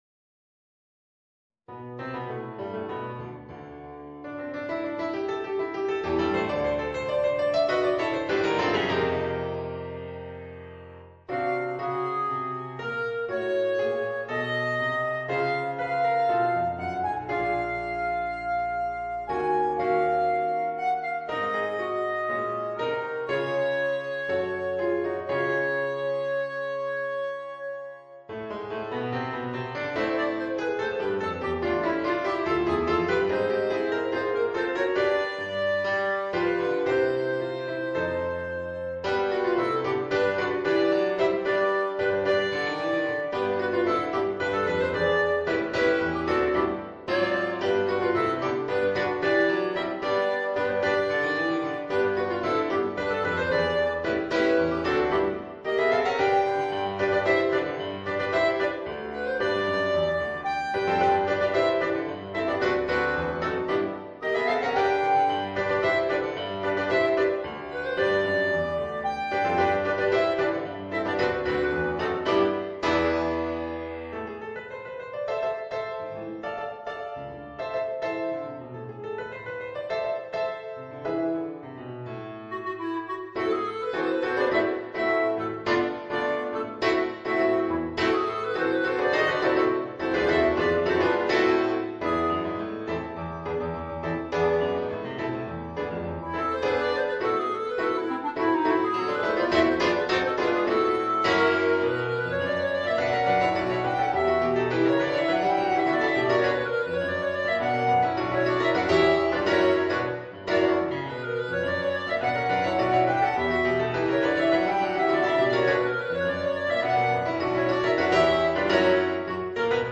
Voicing: Clarinet and Piano